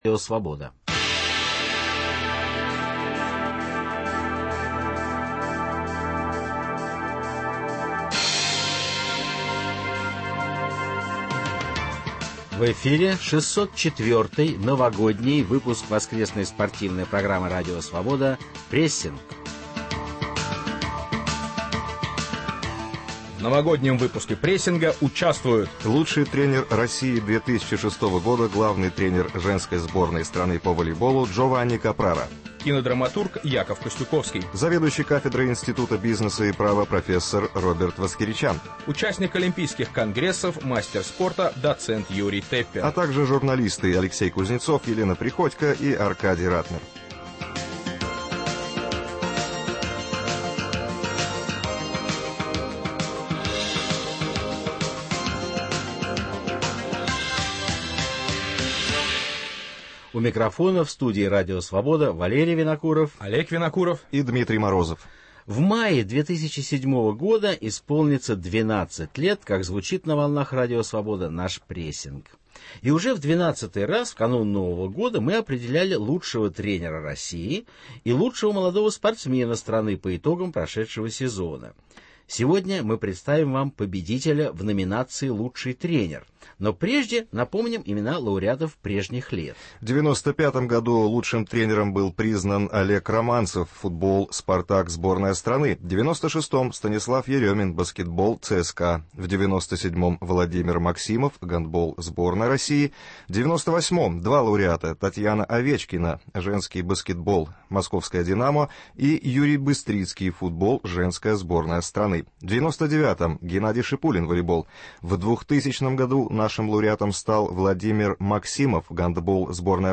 В спортивной программе "Прессинг" - не только свежая информация, анализ и размышления, но и голоса спортсменов и тренеров всего мира с откровениями о жизни, о партнерах и соперниках. Речь не только о самом спорте, ибо он неотделим от социальных, экономических, нравственных и национальных проблем.